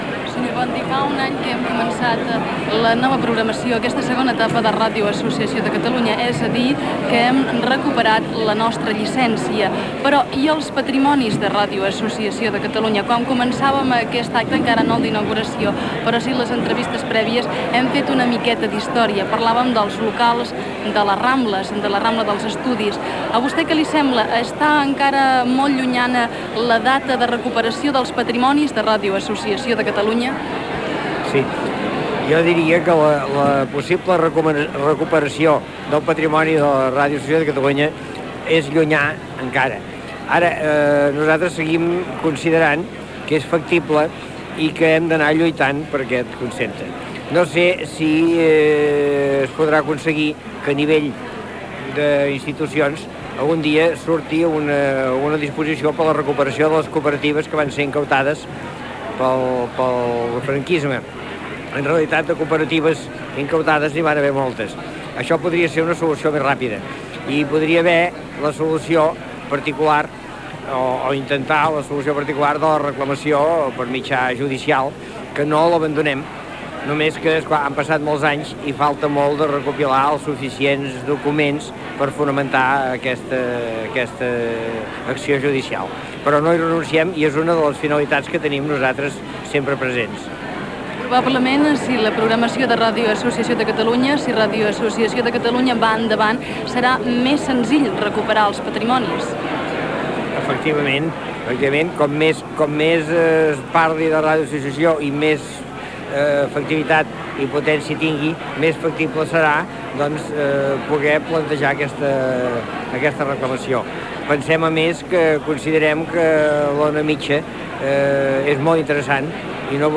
Transmissió de la inauguració de l'exposició "60 anys de Ràdio Associació de Catalunya", des del Palau Marc de Barcelona
Informatiu